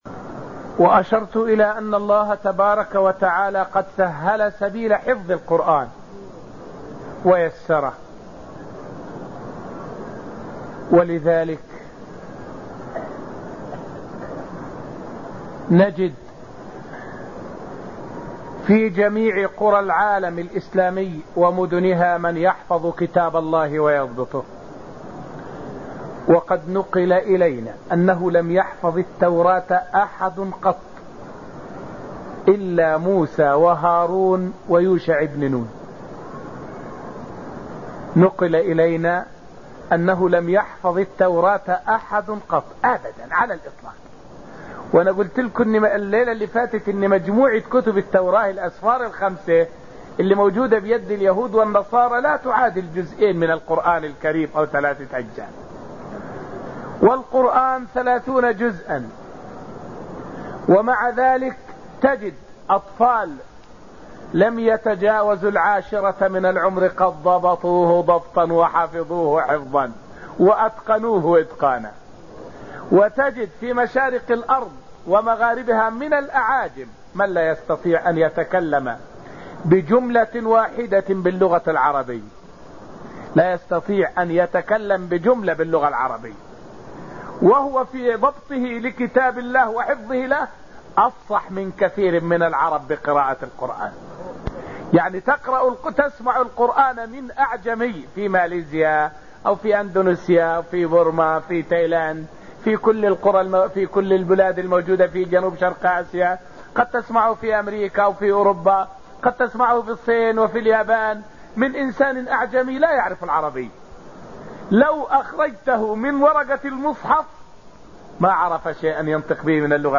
فائدة من الدرس الثاني من دروس تفسير سورة الرحمن والتي ألقيت في المسجد النبوي الشريف حول أجر من يحفظ الآية والآيتين من كتاب الله عز وجل.